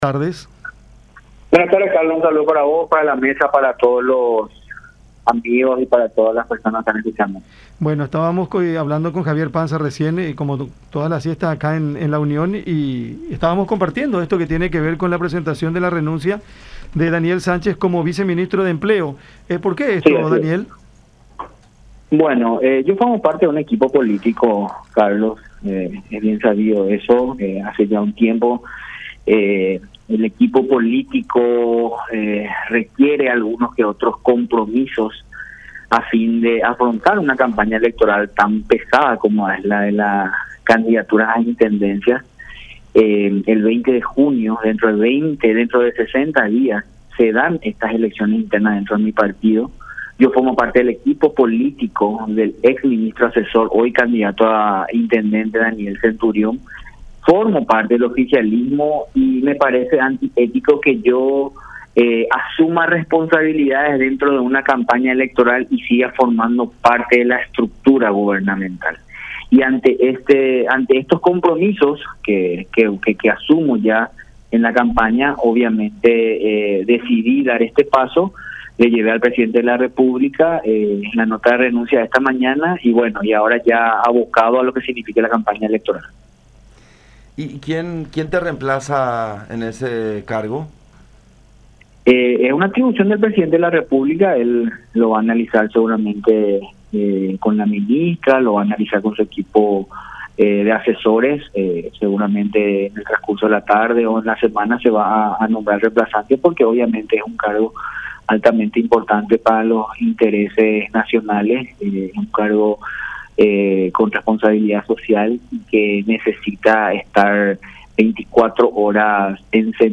Es por ello que esta mañana he llevado mi carta de renuncia al presidente de la República, Mario Abdo, que será el que designe al sucesor”, expuso Sánchez en conversación con La Unión, haciendo referencia a que se abocará a hacer campaña por Centurión con el objetivo de llegar a la intendencia capitalina.